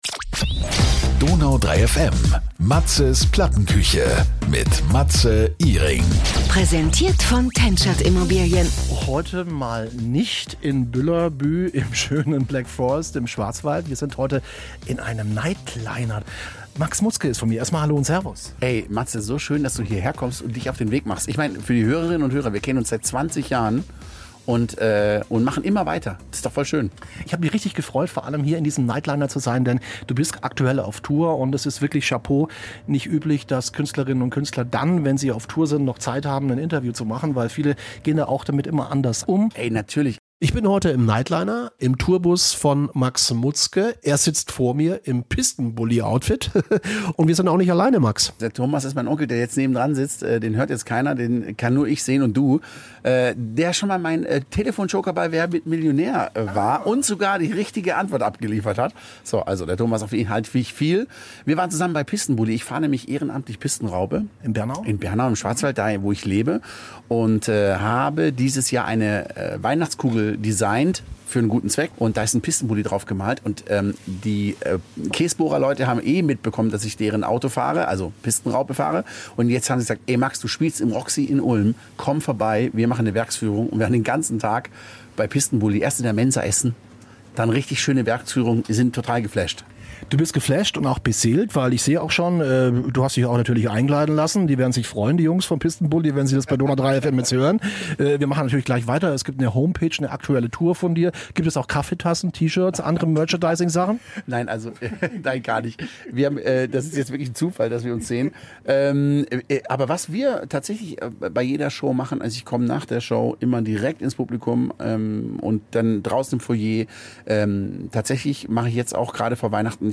Max Mutzke ist zu Gast in der Plattenküche.